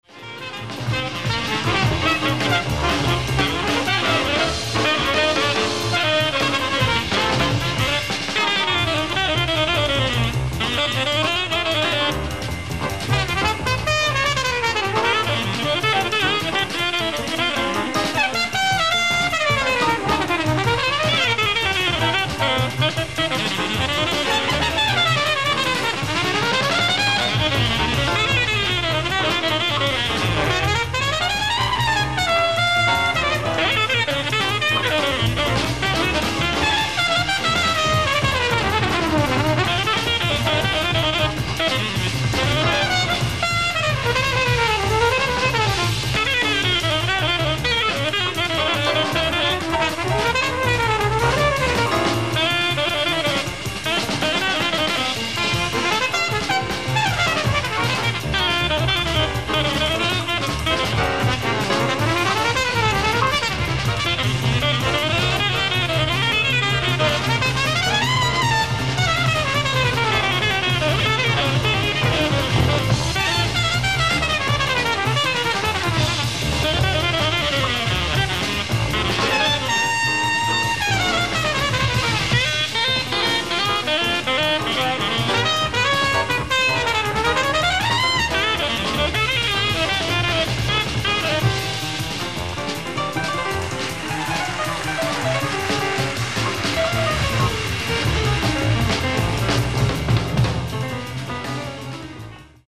ライブ・アット・エドモントン・ジャズ、エドモントン、カナダ 07/24/1986
※試聴用に実際より音質を落としています。